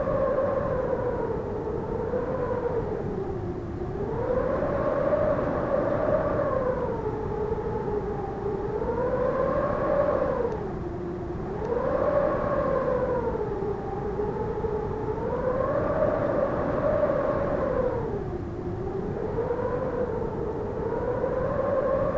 Wind_Medium.ogg